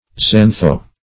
Search Result for " xantho-" : The Collaborative International Dictionary of English v.0.48: Xantho- \Xan"tho-\ A combining form from Gr. xanqo`s yellow; as in xanthocobaltic salts.